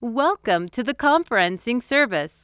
conference_greeting.wav